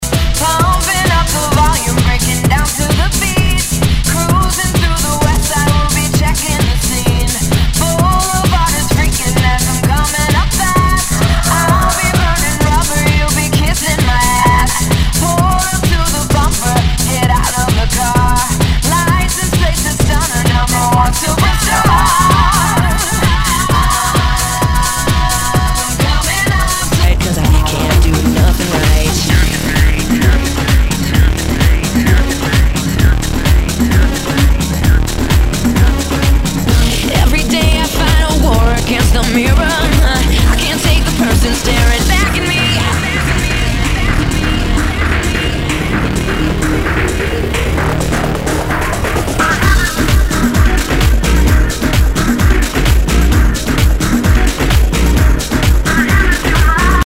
HOUSE/TECHNO/ELECTRO
ナイス！ハウス・ミックス！